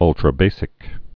(ŭltrə-bāsĭk)